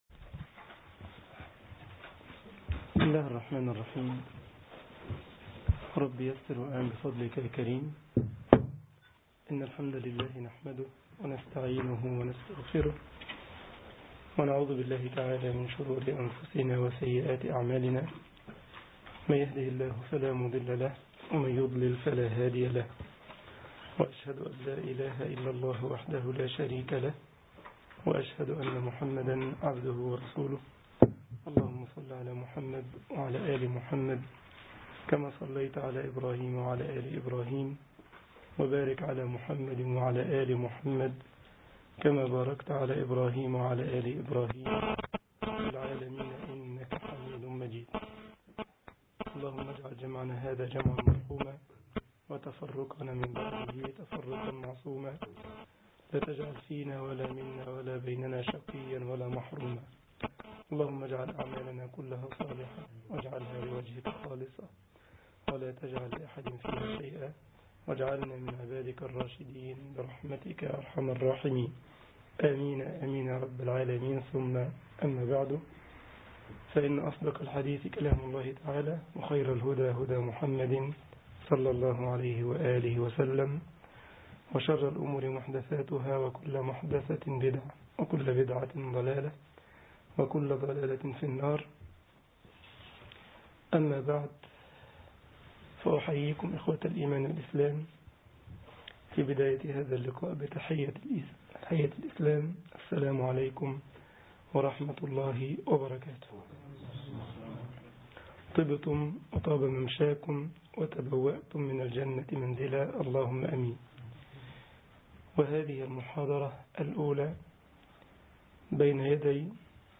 ثلاثة الأصول 1 طباعة البريد الإلكتروني التفاصيل كتب بواسطة: admin المجموعة: ثلاثة الأصول Download محاضرة 01 سبتمبر 2013 جمعية الشباب المسلمين بسلزبخ ـ ألمانيا التفاصيل نشر بتاريخ: الإثنين، 23 أيلول/سبتمبر 2013 18:46 الزيارات: 2516 السابق